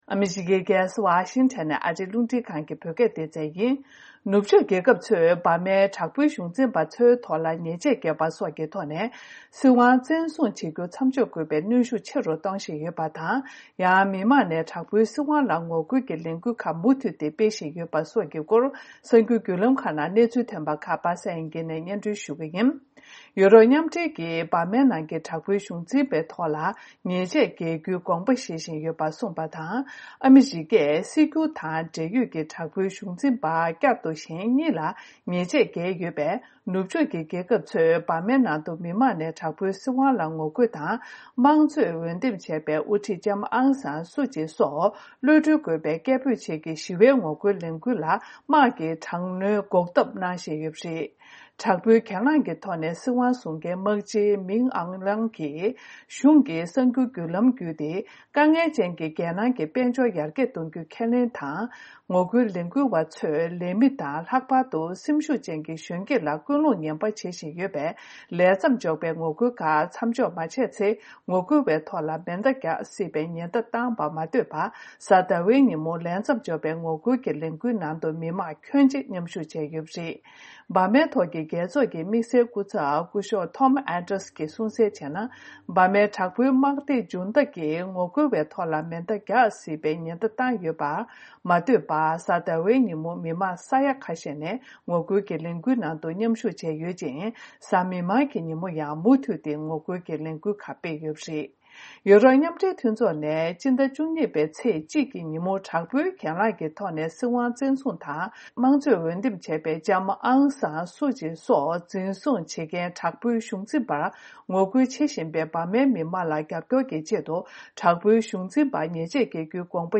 ཕྱོགས་བསྒྲིགས་དང་སྙན་སྒྲོན་ཞུ་ཡི་རེད།